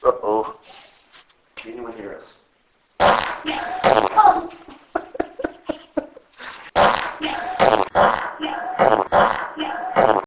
Flatulent Ghost - This clip is not real, and is our investigators having fun, almost too much fun.